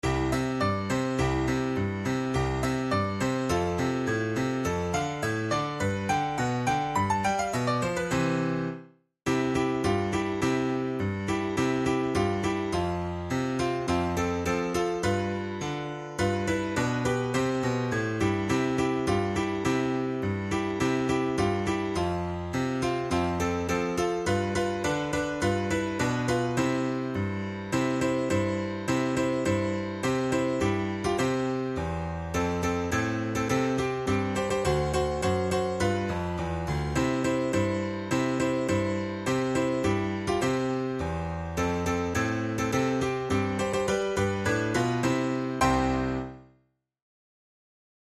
Categories: Christmas carols Difficulty: easy